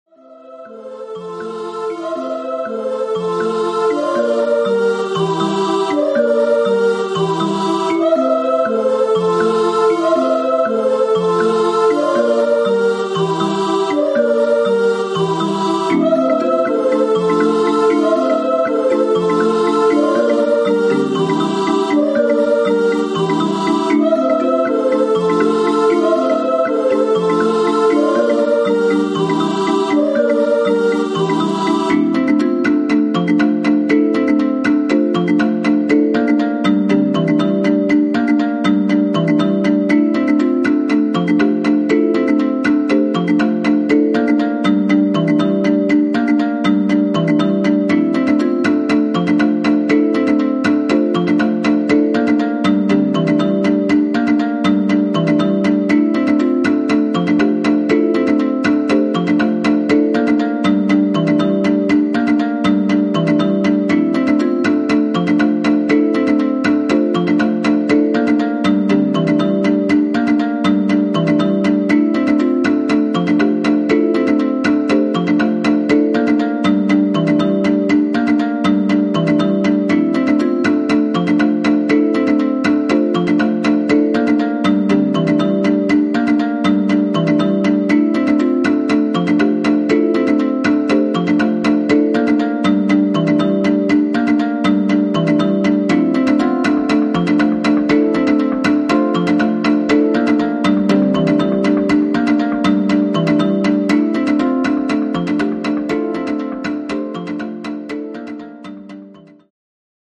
JAPANESE / TECHNO & HOUSE / NEW RELEASE(新譜)